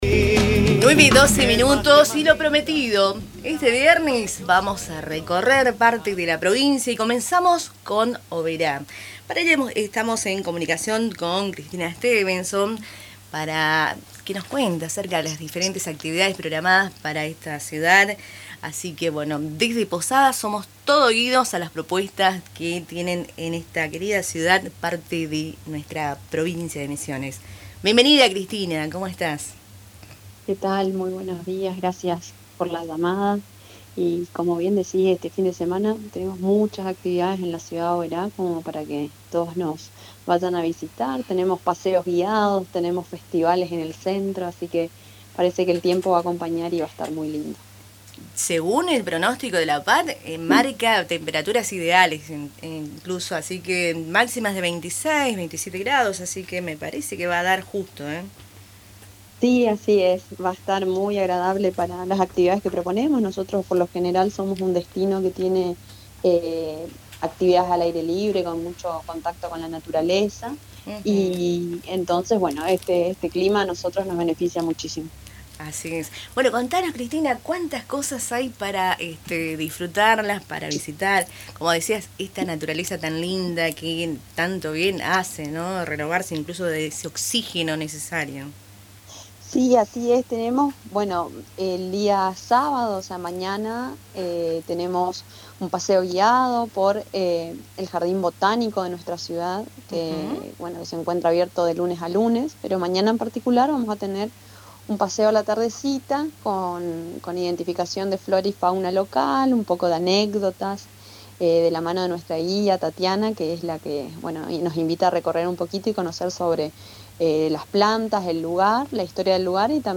Para comenzar con la entrevista